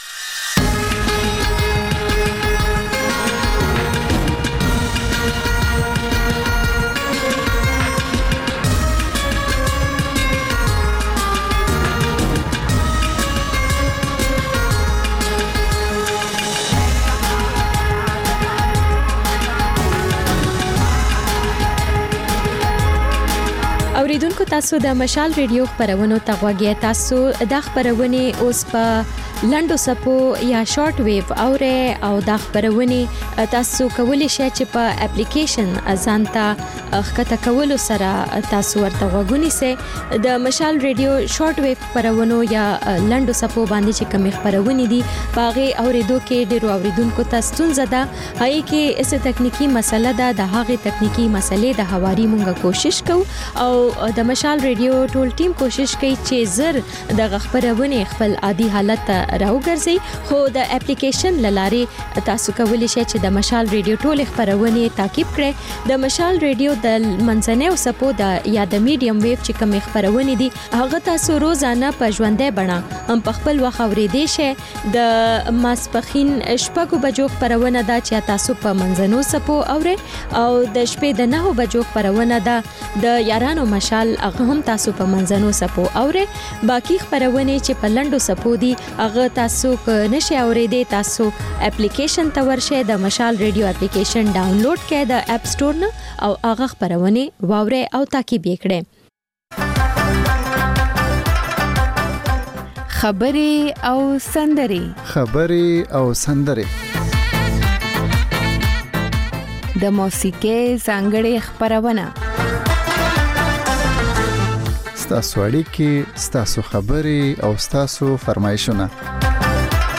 دلته د خبرې او سندرې خپرونې تکرار اورئ. په دې خپرونه کې له اورېدونکو سره خبرې کېږي، د هغوی پیغامونه خپرېږي او د هغوی د سندرو فرمایشونه پوره کېږي.